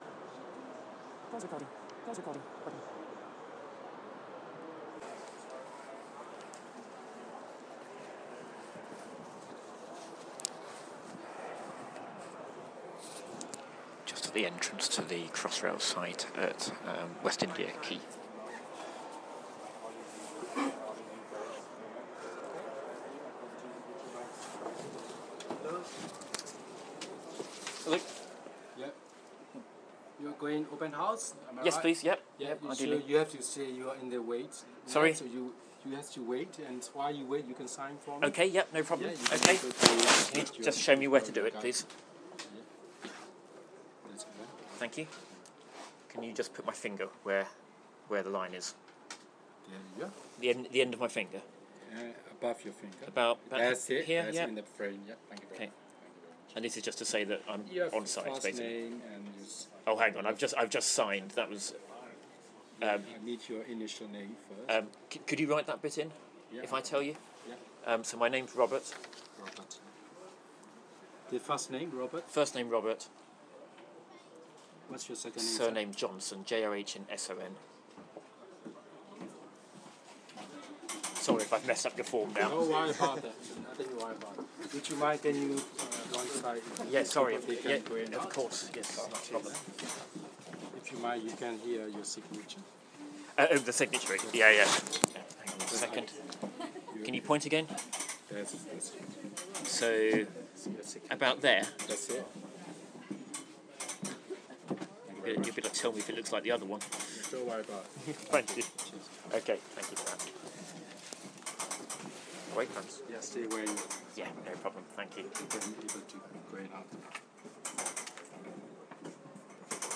Checking into the Canary Wharf Crossrail site during Open House London 2013